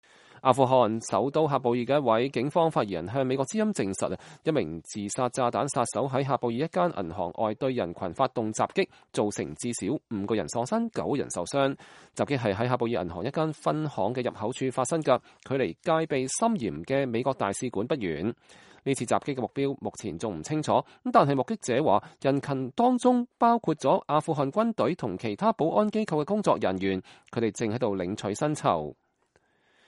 2017-08-29 美國之音視頻新聞: 喀布爾銀行外發生自殺襲擊 5人喪生 (粵語)